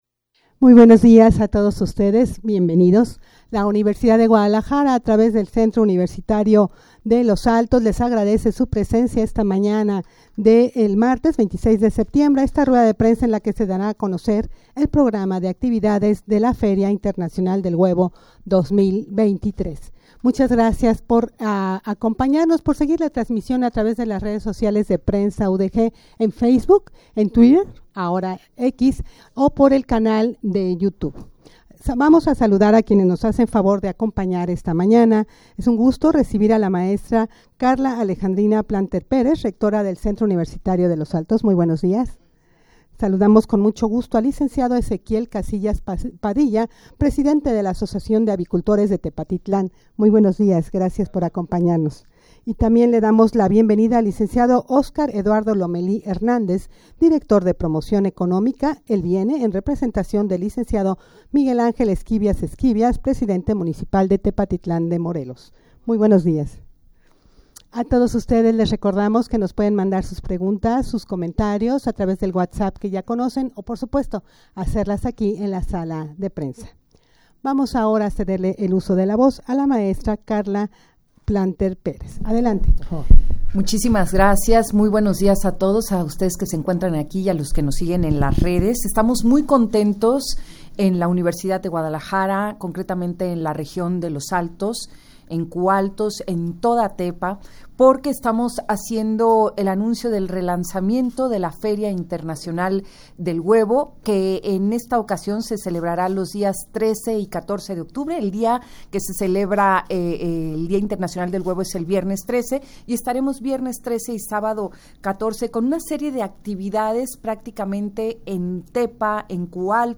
Audio de la Rueda de Prensa
rueda-de-prensa-para-dar-a-conocer-el-programa-de-actividades-de-la-feria-internacional-del-huevo-2023.mp3